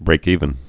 (brākēvən)